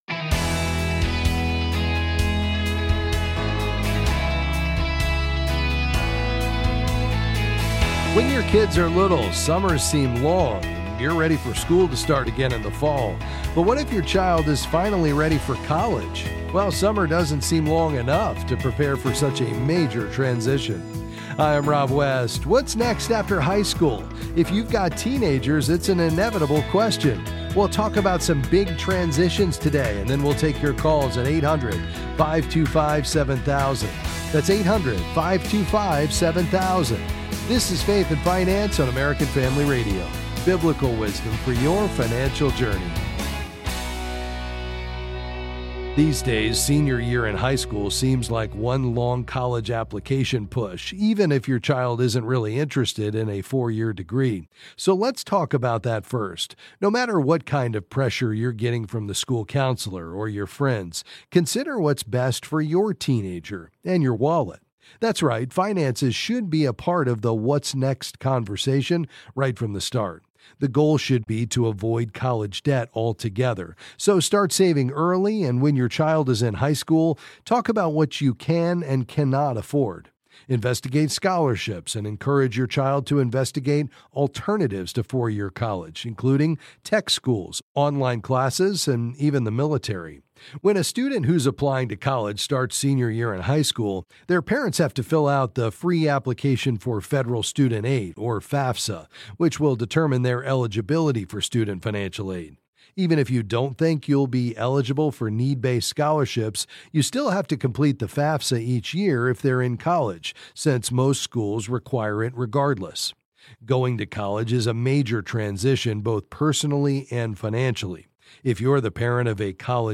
Then, he takes calls and answers various financial questions.